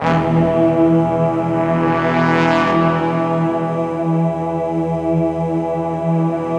SI1 BRASS00L.wav